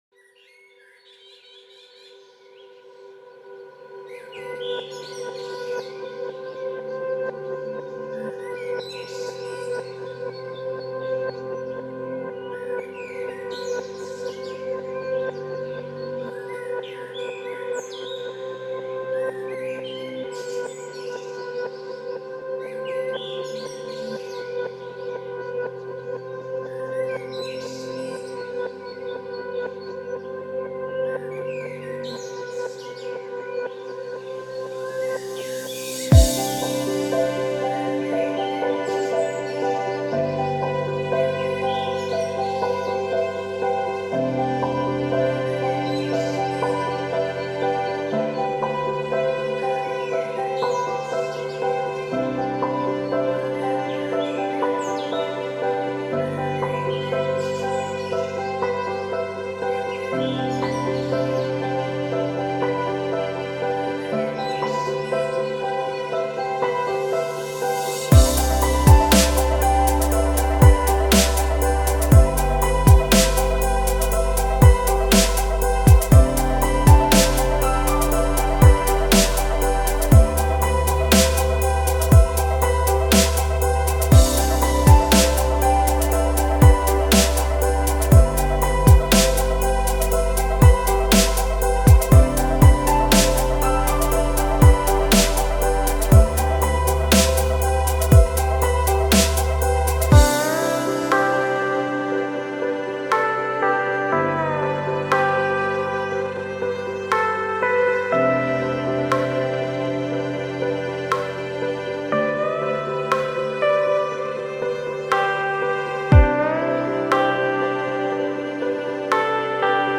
это атмосферная композиция в жанре эмбиент